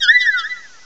cry_not_spritzee.aif